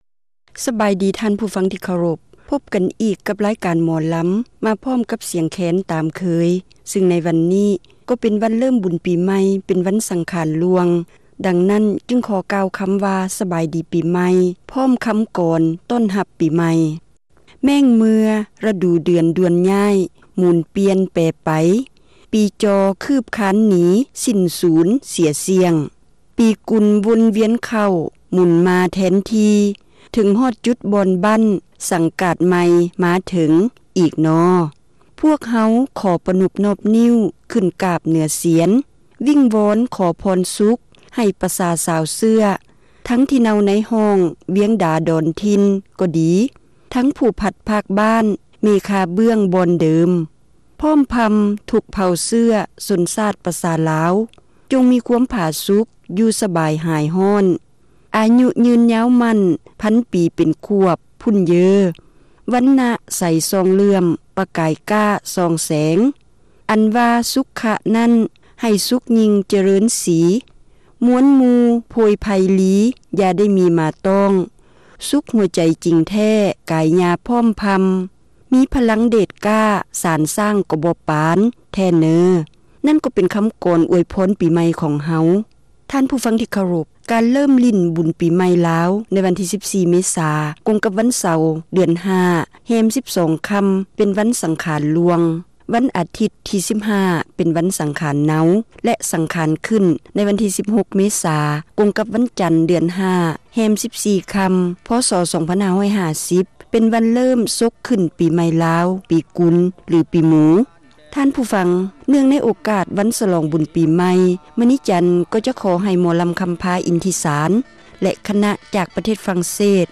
ຣາຍການໜໍລຳ ປະຈຳສັປະດາ ວັນທີ 13 ເດືອນ ເມສາ ປີ 2007